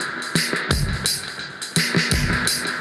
Index of /musicradar/dub-designer-samples/85bpm/Beats
DD_BeatFXA_85-02.wav